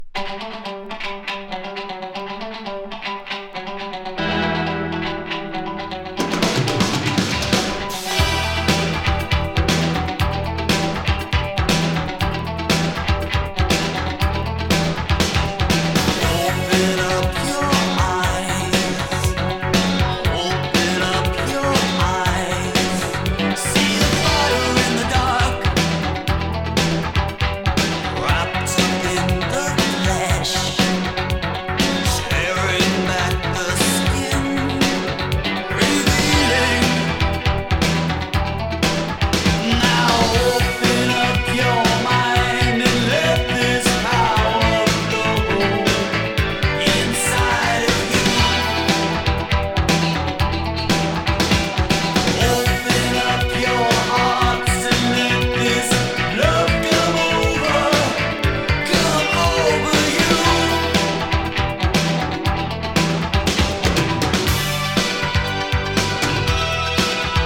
耽美ロック